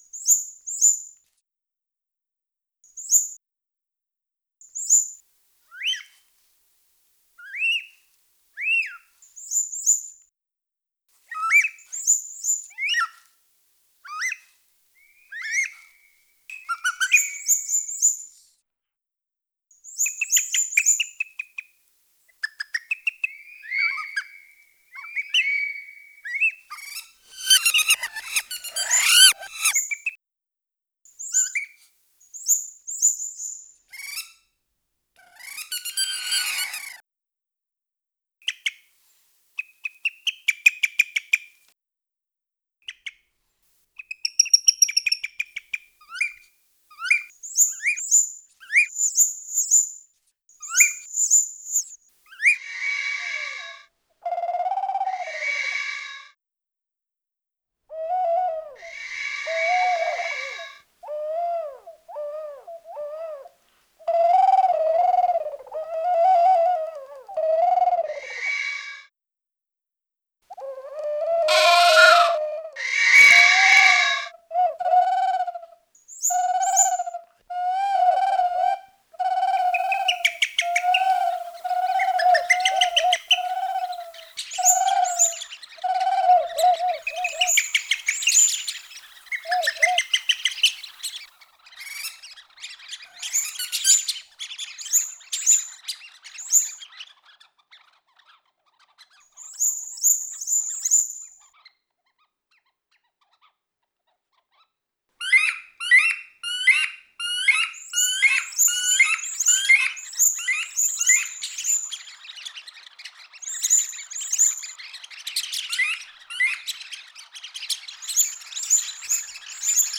Extrait sonore de captures d’appeaux interprétées